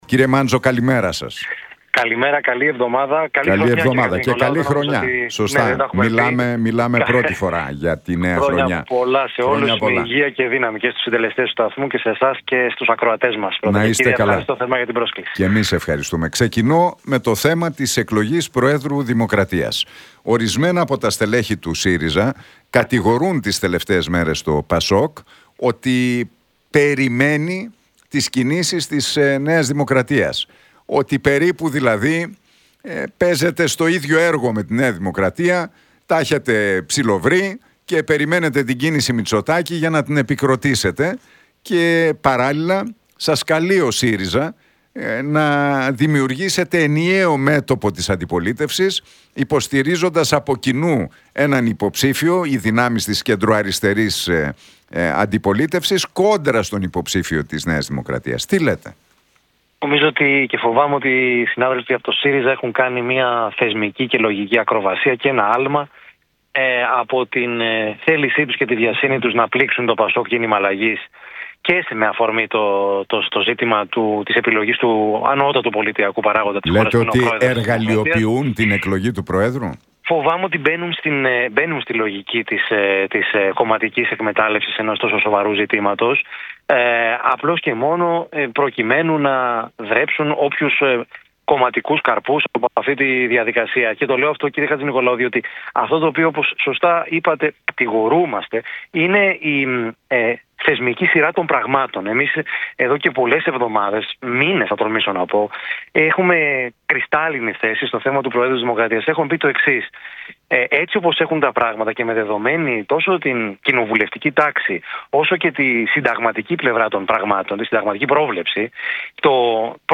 Μάντζος στον Realfm 97,8 για Προεδρία της Δημοκρατίας: Οι συνάδελφοι του ΣΥΡΙΖΑ μπαίνουν στη λογική της κομματικής εκμετάλλευσης ενός τόσο σοβαρού ζητήματος